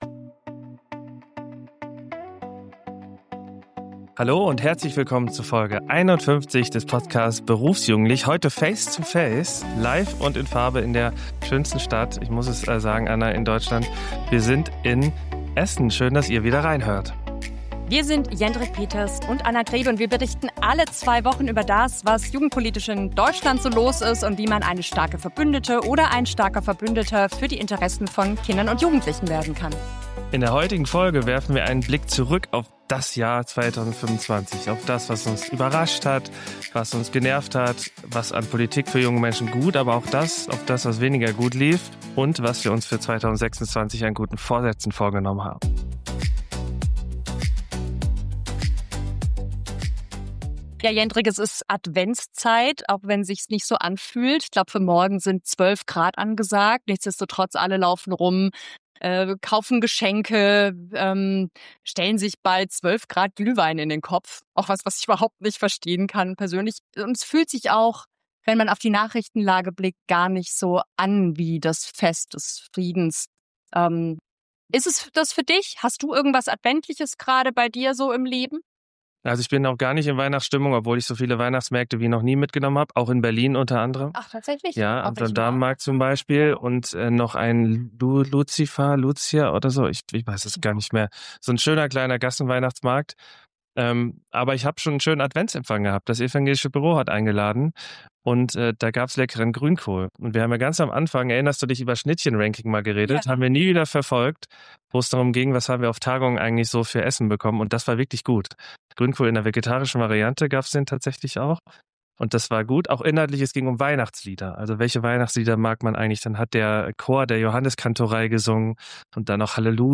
in persönlicher Face-to-Face-Atmosphäre